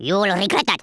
Worms speechbanks
youllregretthat.wav